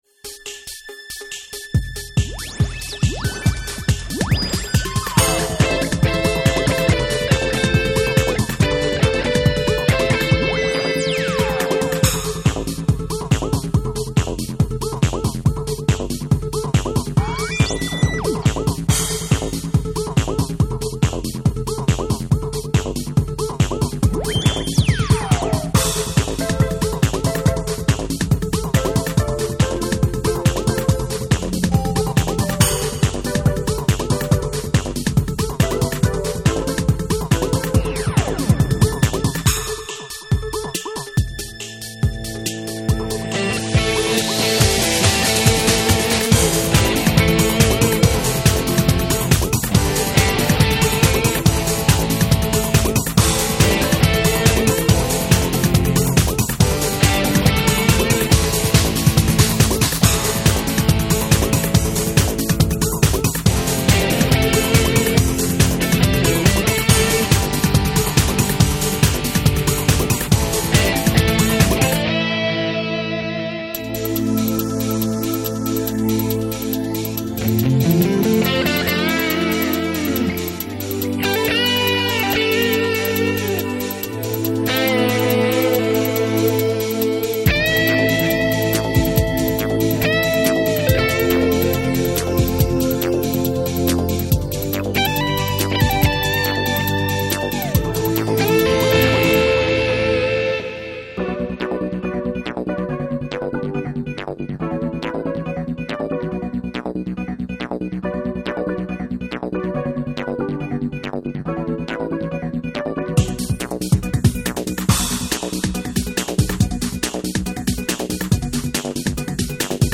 (Karaoké) MP3